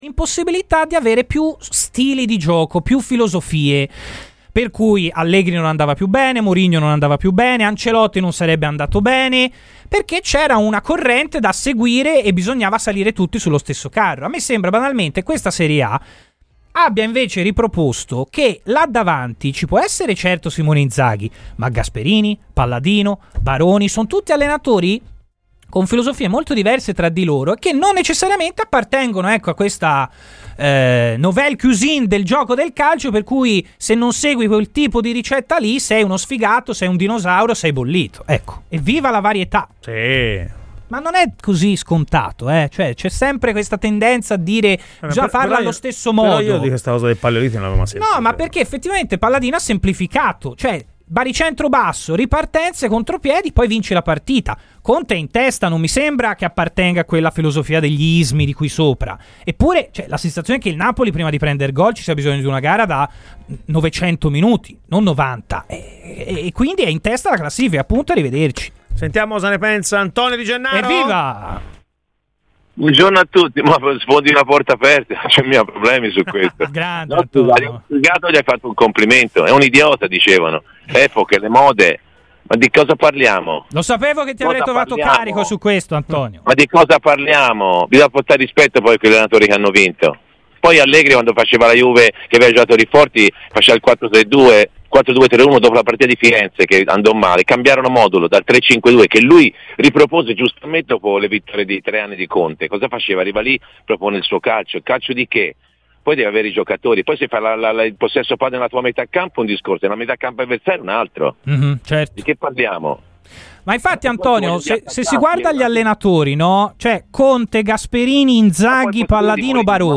ASCOLTA IL PODCAST PER L'INTERVISTA COMPLETA Antonio Di Gennaro a Radio FirenzeViola